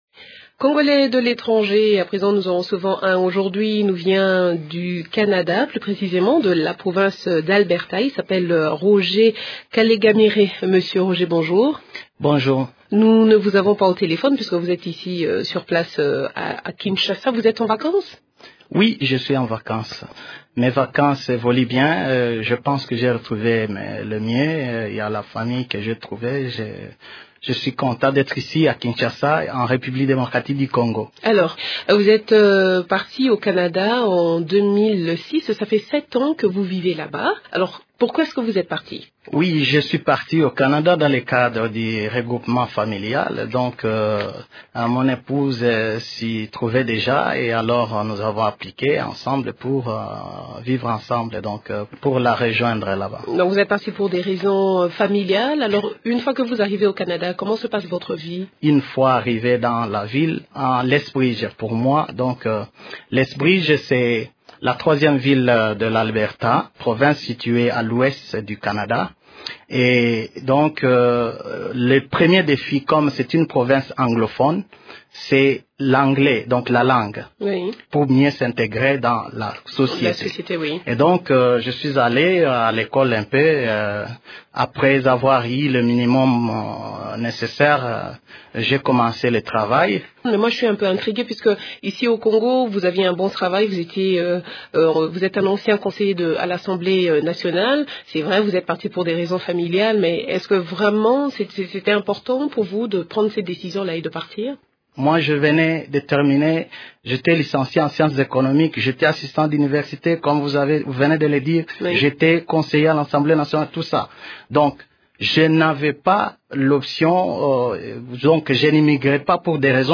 Congolais vivant au Canada reçu au studio de Radio Okapi à Kinshasa (Janvier 2013)